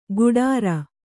♪ guḍāra